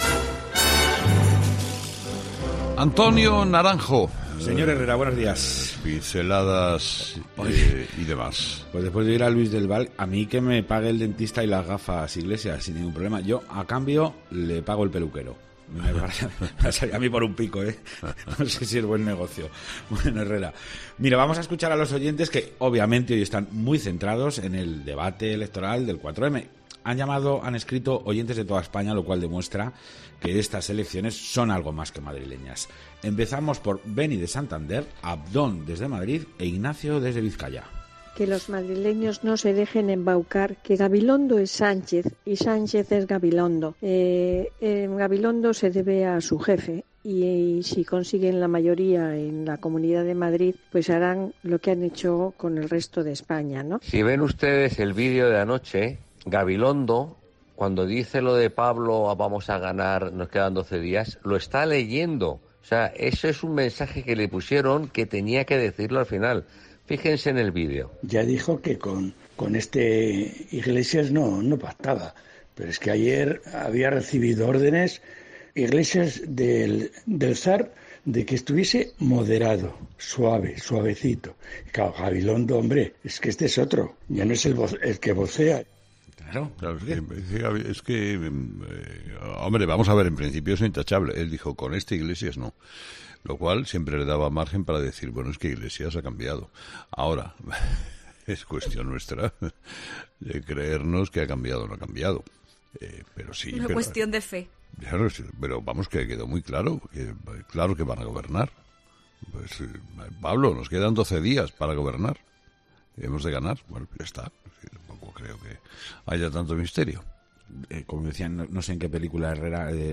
Los oyentes, de nuevo, protagonistas en 'Herrera en COPE' con su particular tertulia.